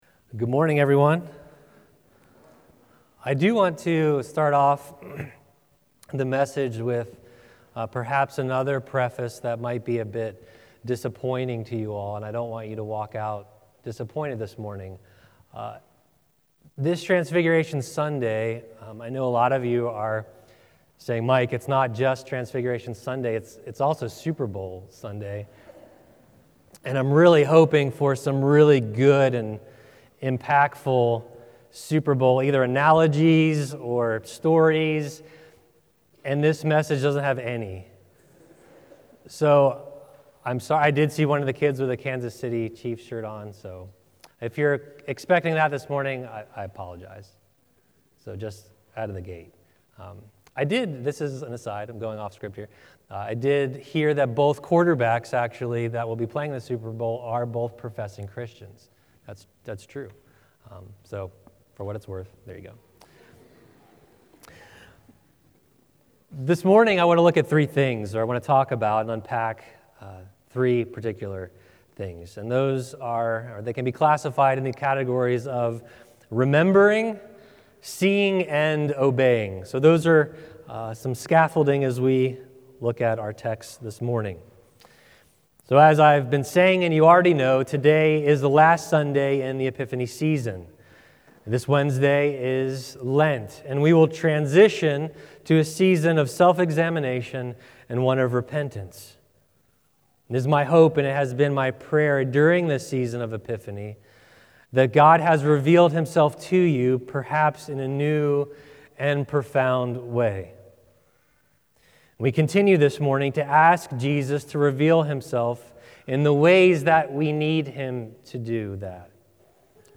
Download Download Epiphany 2024 Current Sermon Transfiguration Sunday